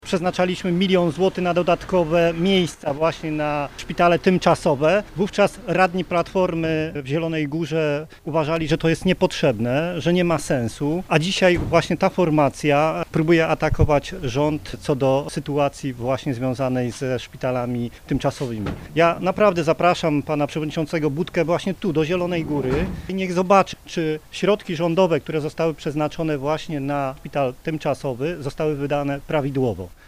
Na konferencji prasowej przed zielonogórskim szpitalem covidowym politycy i samorządowcy Prawa i Sprawiedliwości zwracali uwagę na krytykanctwo i brak merytorycznych uwag opozycji.